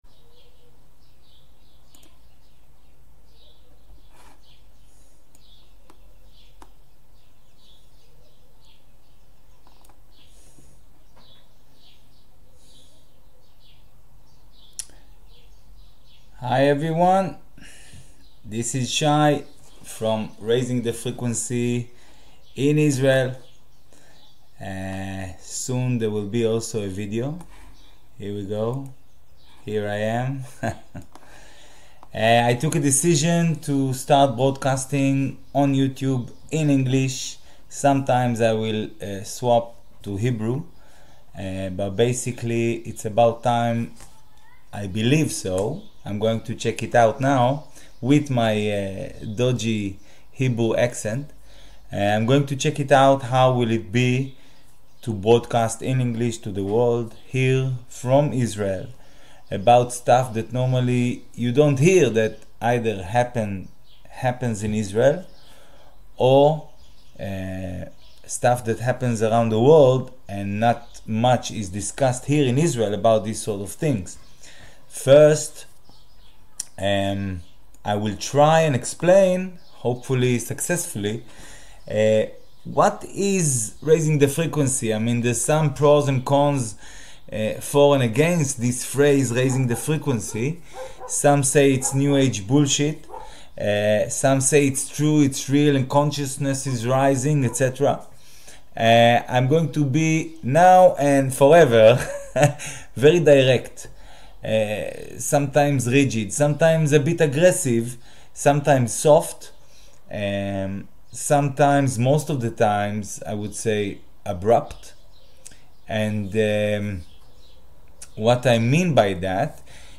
Raising The Frequency live from israel